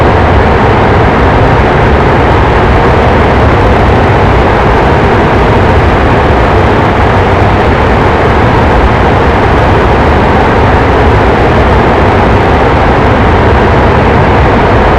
A320-family/Sounds/SASA/CFM56B/cockpit/cfm-reverse.wav at 9d375991ed13a36578b302d3bbe1c16c35216f80
cfm-reverse.wav